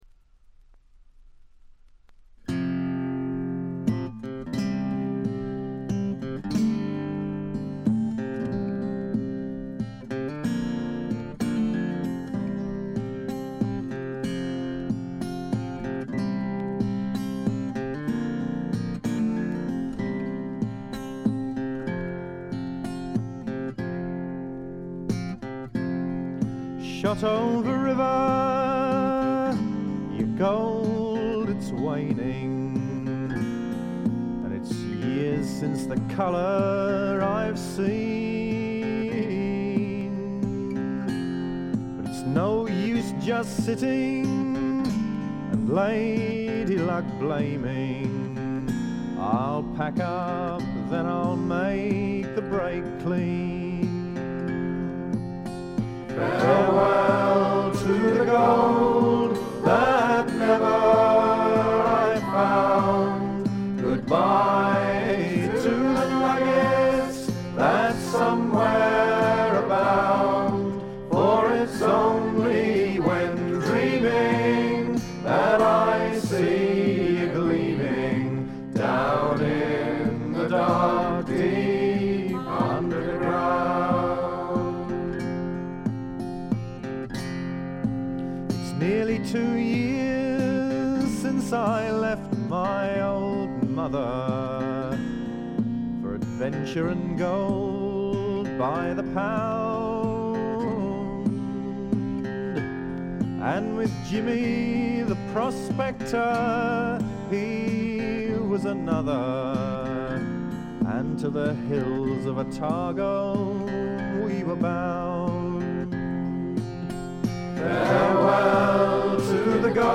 チリプチ少々。目立つノイズはありません。
芳醇で滋味あふれるヴォーカルにギターやフィドルも完璧です。
試聴曲は現品からの取り込み音源です。
Recorded At - Livingston Studios